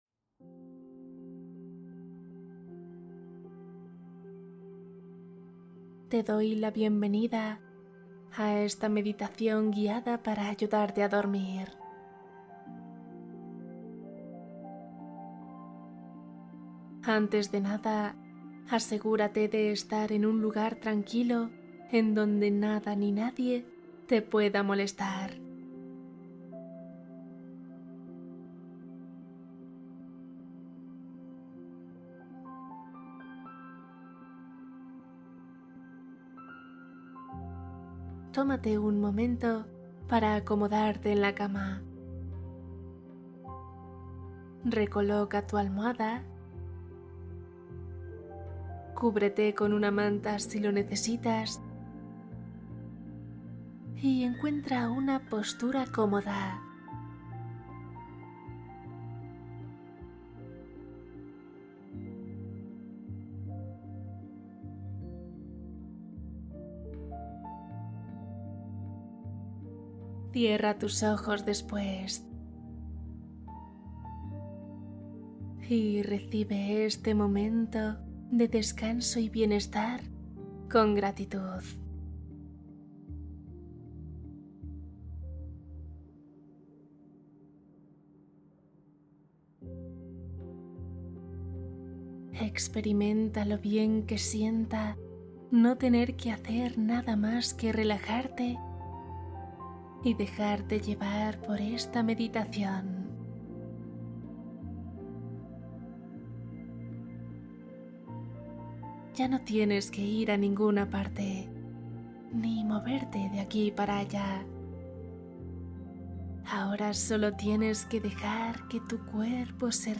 Sueño sin ansiedad: meditación y cuento para dormir profundamente